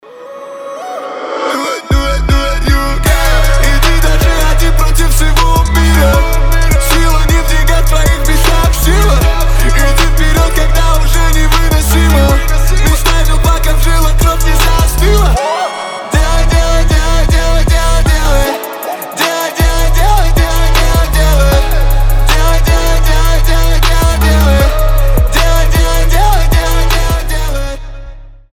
• Качество: 320, Stereo
русский рэп
мотивирующие
Trap
басы